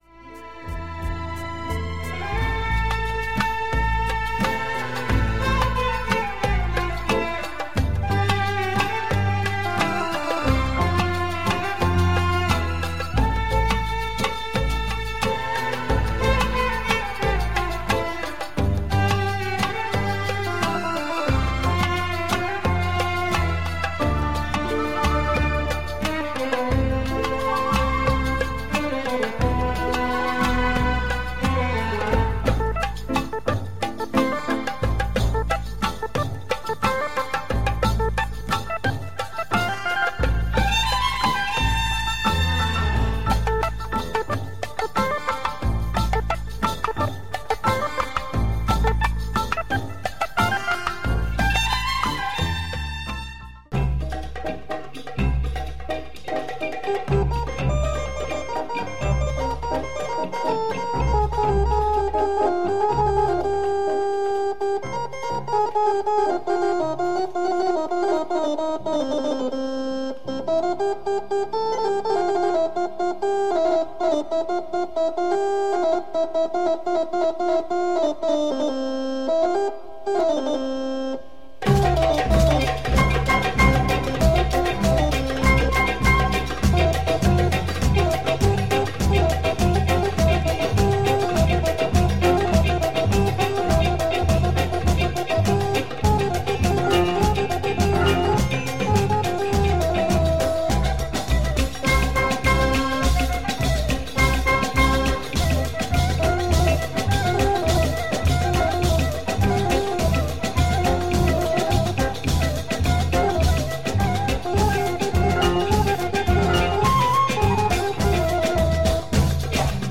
Groovy belly dance tunes with samples and beats.